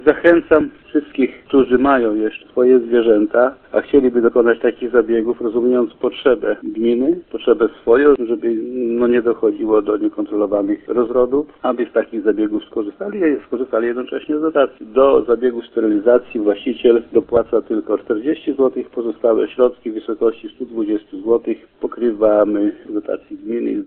– Uzyskane przez gminę dotacje pozwalają zmniejszyć koszty zabiegu nawet o 3/4 ceny – mówi wójt gminy Grajewo Stanisław Szleter.